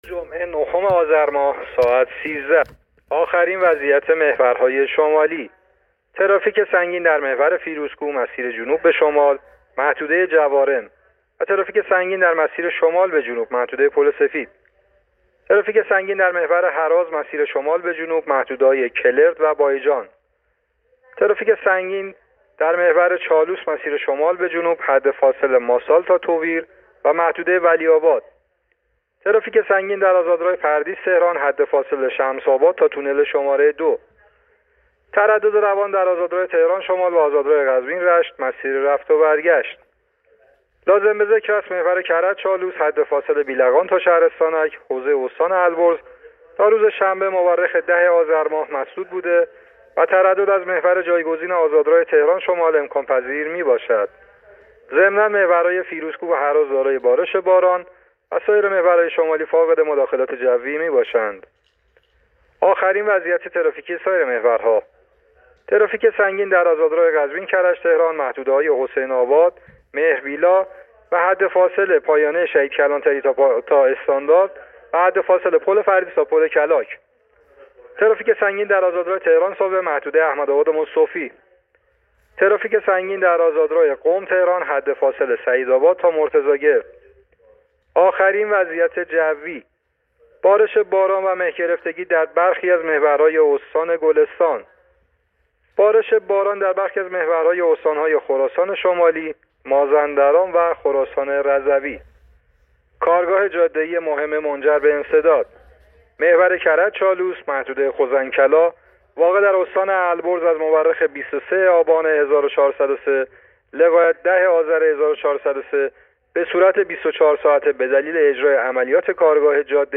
گزارش رادیو اینترنتی از آخرین وضعیت ترافیکی جاده‌ها تا ساعت ۱۳ نهم آذر